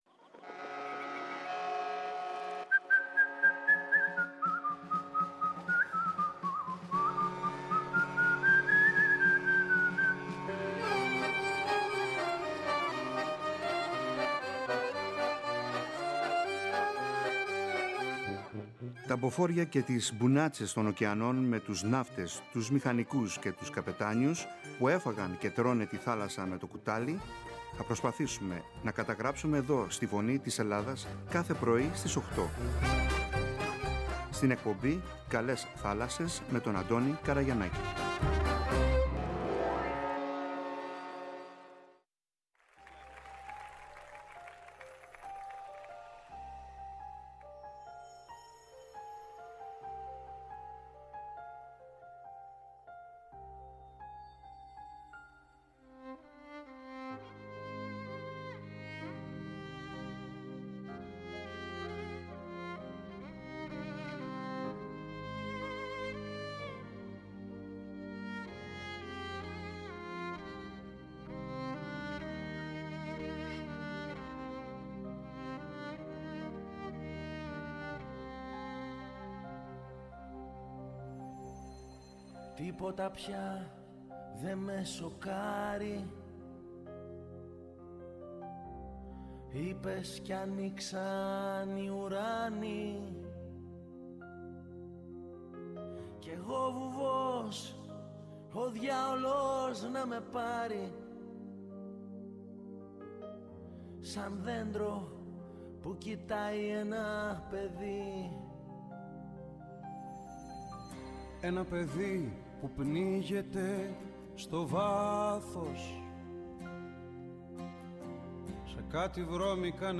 Δύτης – Δασοπυροσβέστης από την Κάρπαθο